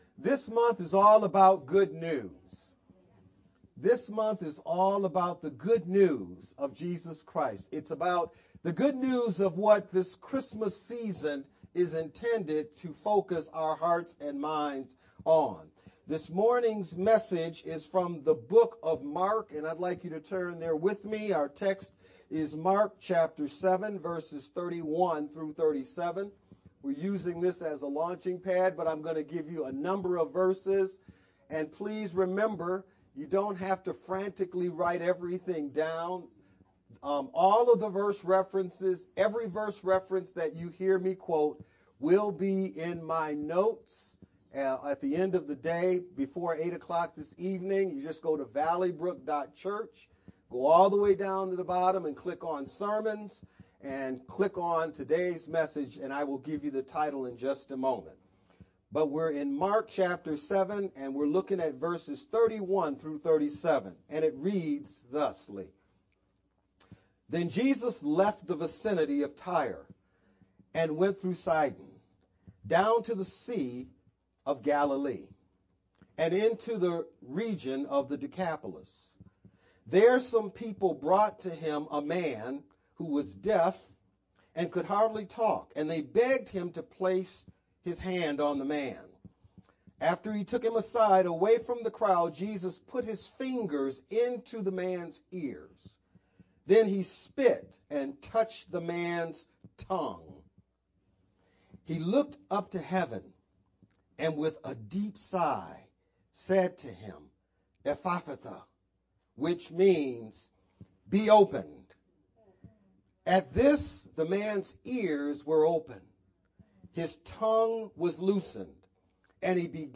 Dec-10th-sermon-only_Converted-CD.mp3